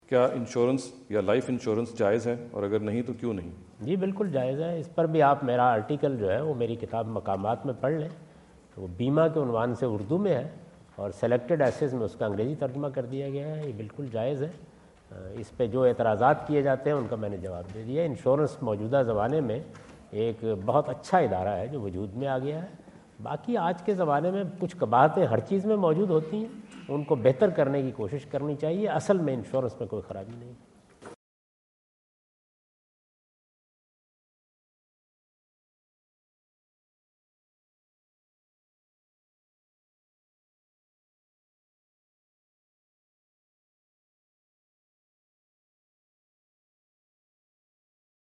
Category: English Subtitled / Questions_Answers /
In this video Javed Ahmad Ghamidi answer the question about "significance of insurance in Islam" asked at The University of Houston, Houston Texas on November 05,2017.